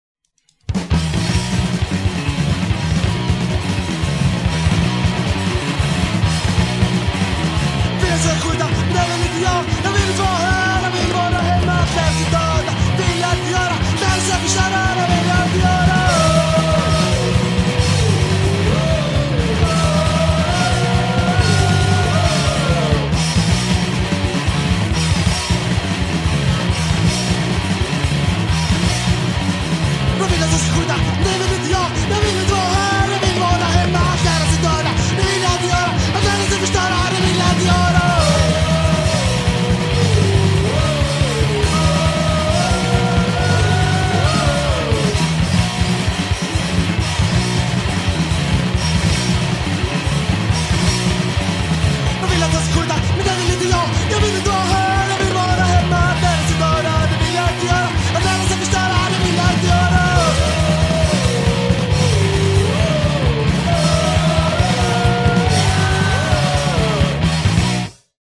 a quartet, who tried to play fast punk
Guitar, voice
Drums